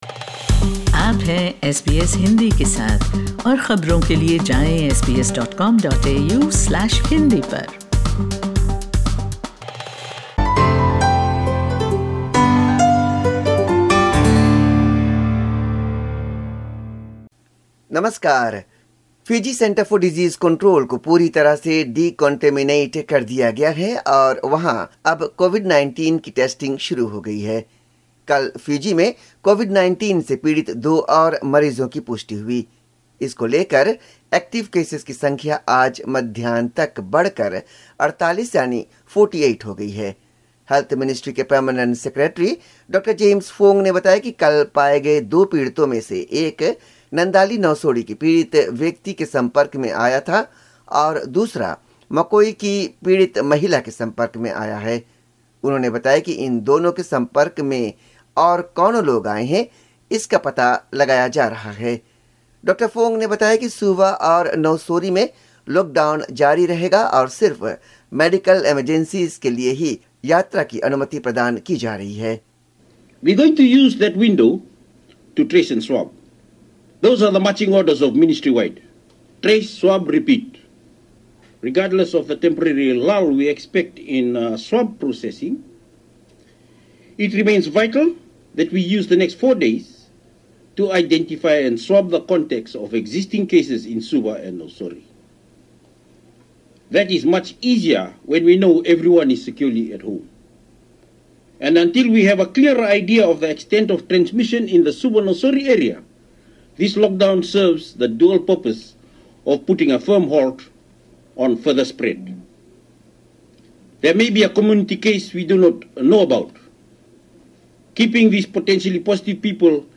Fiji report in Hindi : 16 May 2021